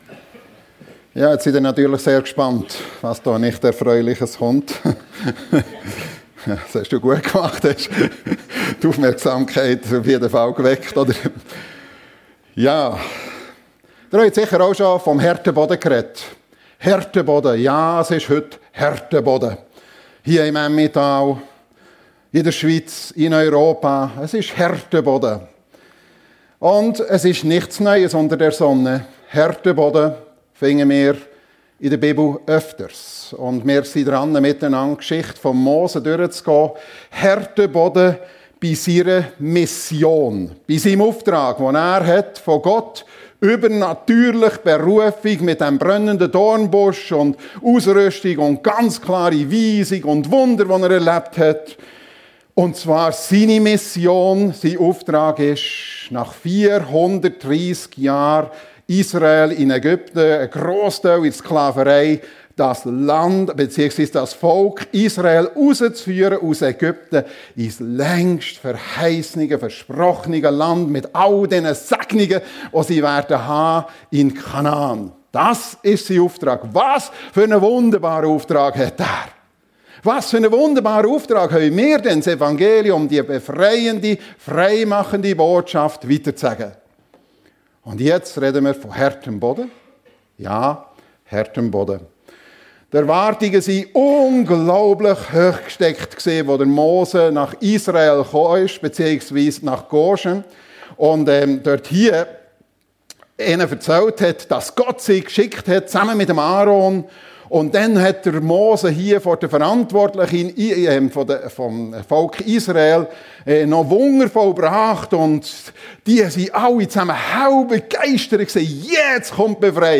FEG Sumiswald - Predigten